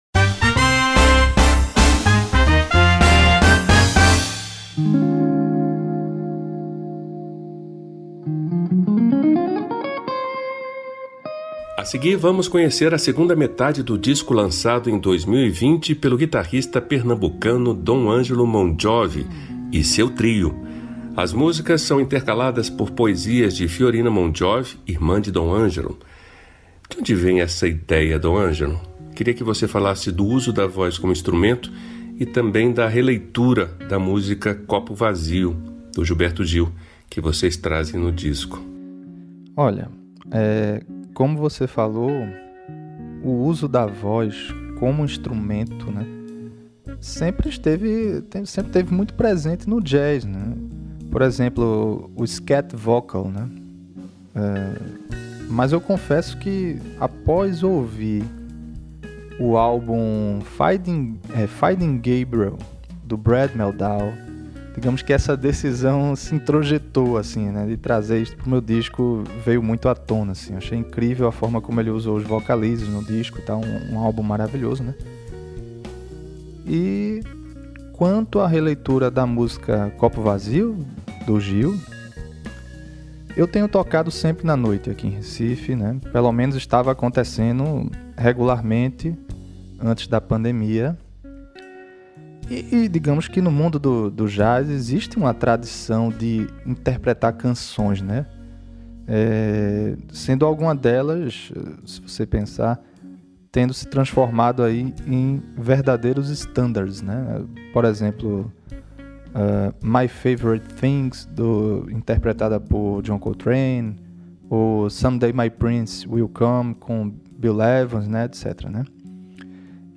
guitarrista
baixista
baterista